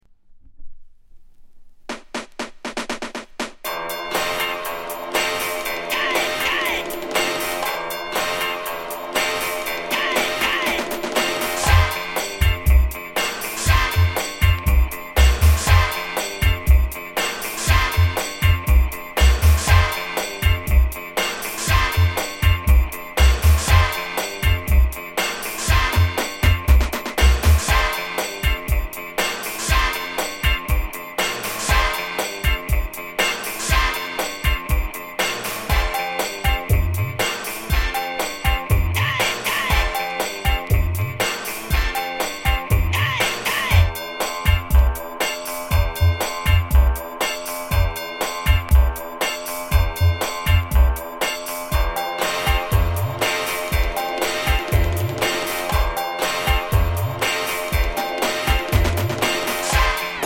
category Reggae